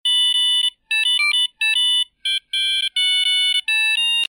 О, кстати, че у себя откопал) Звук пейджера из GTA III Можно на какой-нить канал важный поставить, например)